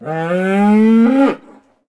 Index of /HCU_SURVIVAL/Launcher/resourcepacks/HunterZ_G4/assets/minecraft/sounds/mob/cow
say3.ogg